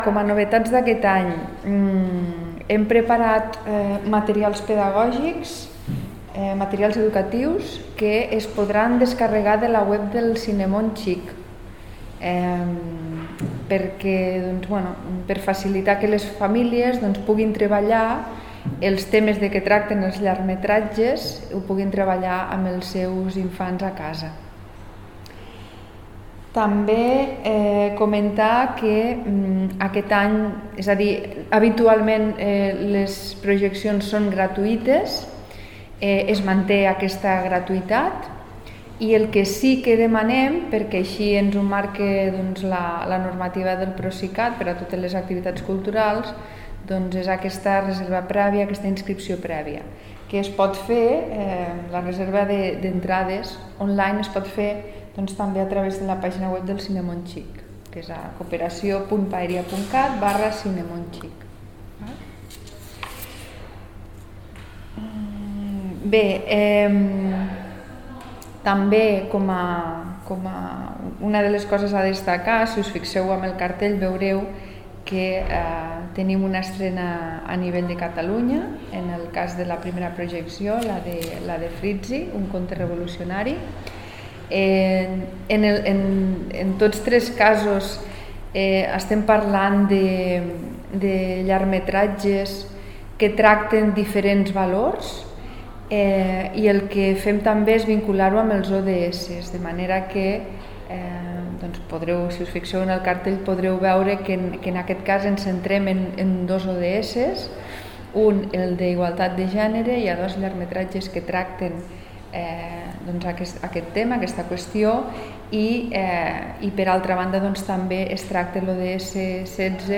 tall-de-veu-de-la-tinent-dalcalde-sandra-castro-sobre-el-5e-cinemon-xic